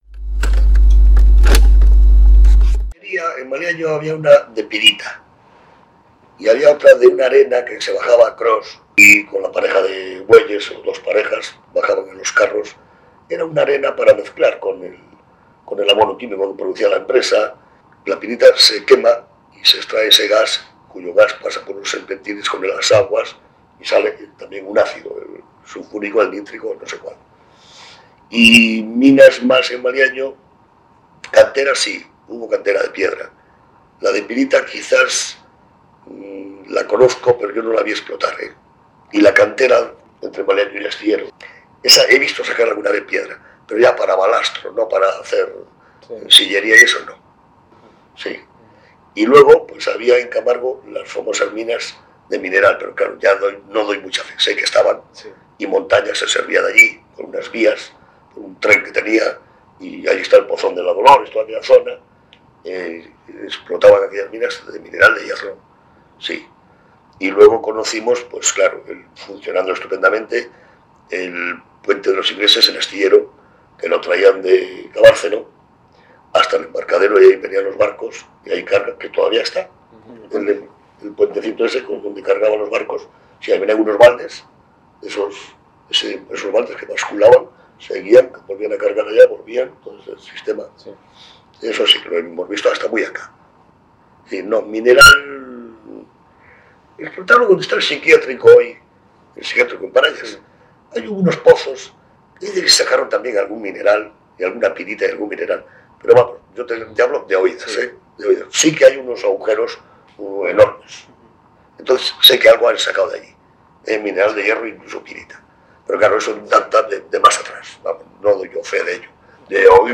Historia oral del valle de Camargo